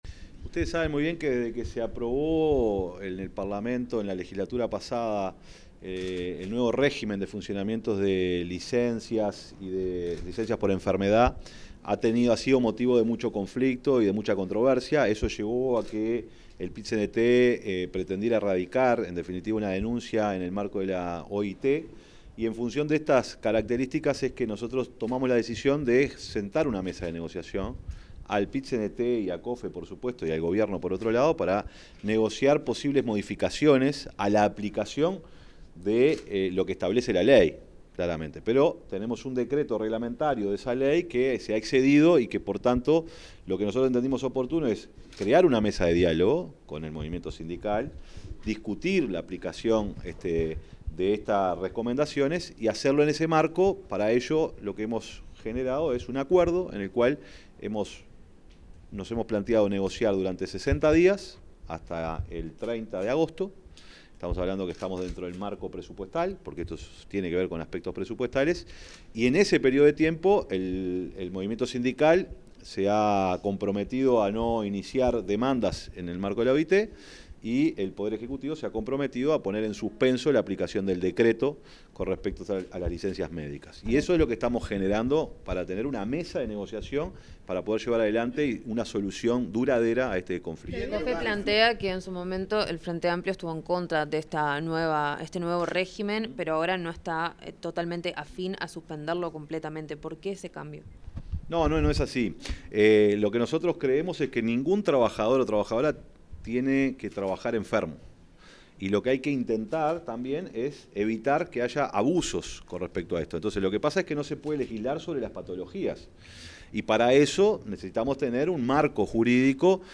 Declaraciones del secretario de Presidencia, Alejandro Sánchez
Luego de una reunión en la Torre Ejecutiva con la Confederación de Funcionarios del Estado (COFE), el secretario de la Presidencia, Alejandro Sánchez,